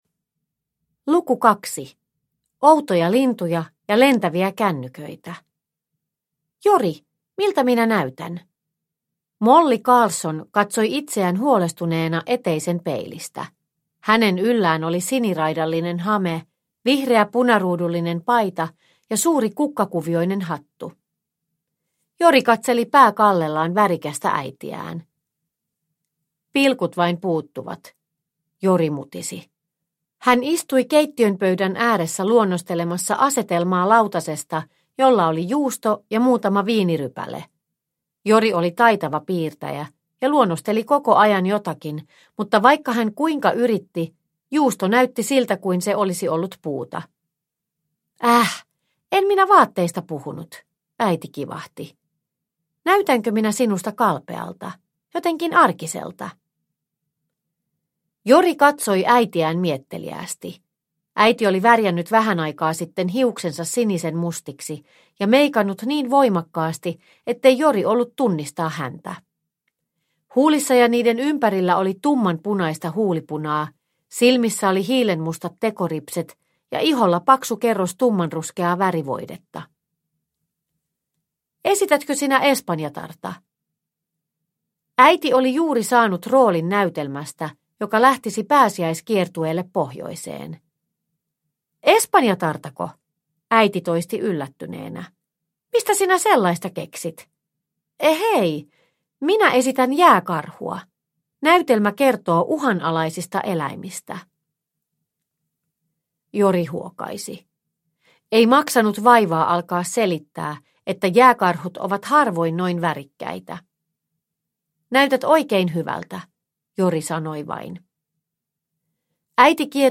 Voroja ja vompatteja – Ljudbok – Laddas ner